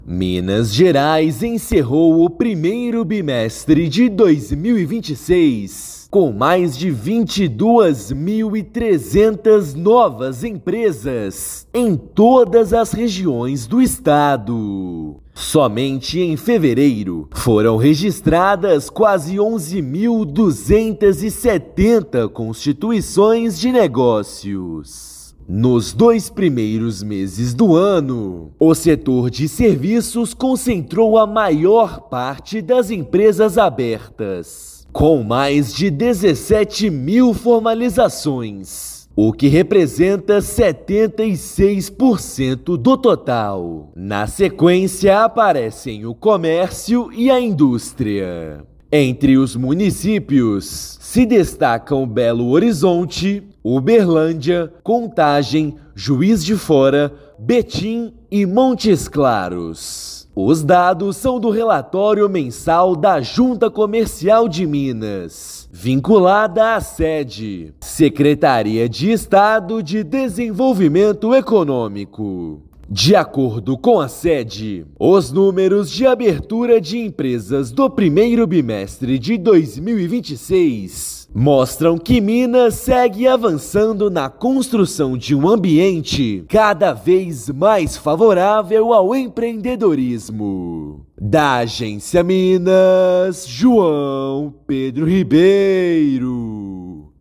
Somente em fevereiro foram registradas 11.267 constituições; setor de serviços responde por 76% do total dos novos negócios formalizados no ano. Ouça matéria de rádio.